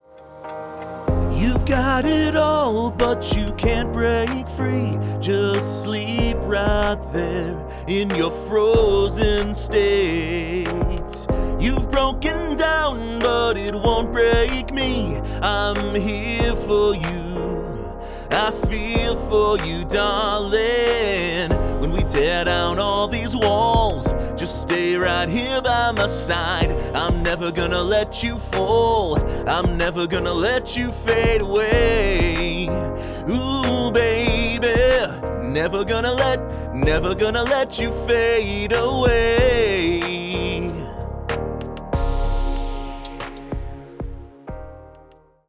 The vocals are recorded with a Golden Age GA-251 tube condenser microphone, going into an Apogee Symphony I/O preamp, and then sent over to a Golden Age Comp-2A optical compressor, which is applying around 2-3 dB of reduction to control the dynamics.
No EQ or other effects have been added to the vocal.
This is a single take and has not been comped together from multiple takes.
Listen to the following musical examples: Version A is my raw vocal recording with the backing track, Version B is a fully tuned vocal with Melodyne, using features that are all included with the Assistant version of Melodyne, and Version C is a fully tuned vocal with Flex Pitch.
01-Raw-Vocal-Music_01.mp3